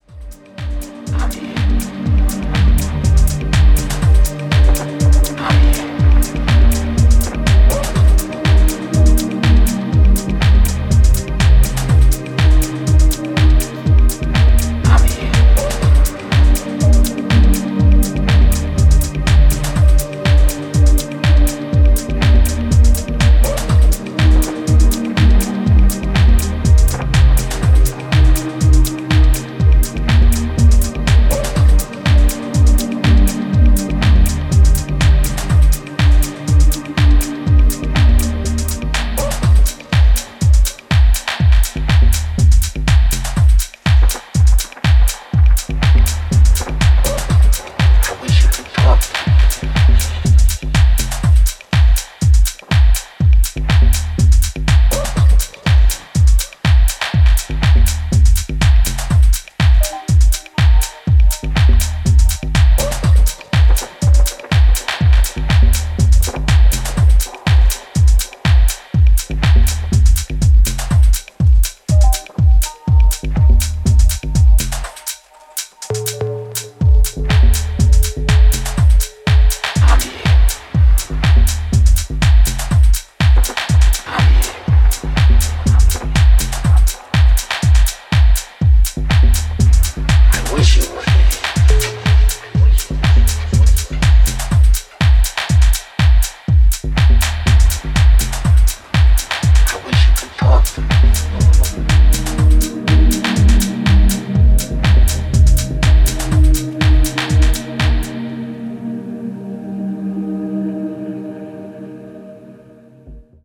各曲アプローチを絶妙に変えながらもアトモスフェリックかつ幻想的なトーンを保ち続けていますね。